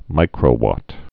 (mīkrō-wŏt)